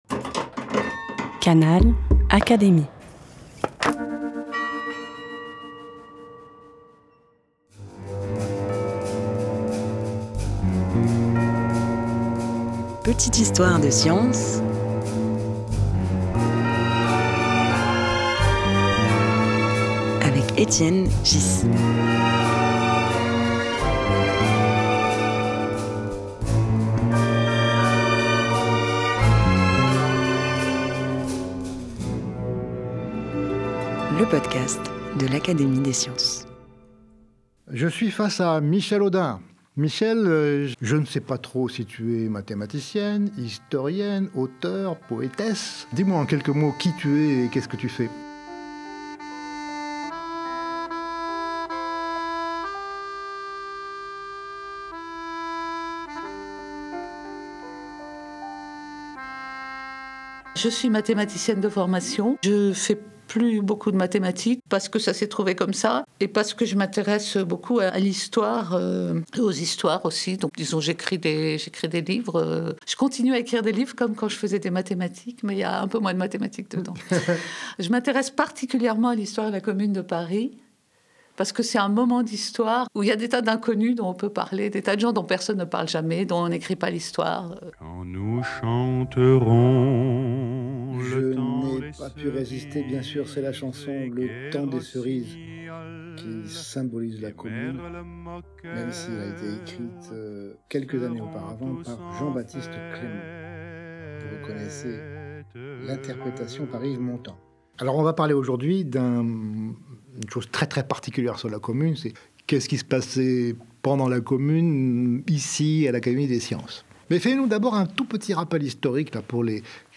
Au micro d’Étienne Ghys, l’historienne Michèle Audin décrit l’activité de l’Académie des sciences pendant la Commune de Paris en 1871.
Un podcast animé par Étienne Ghys, proposé par l'Académie des sciences.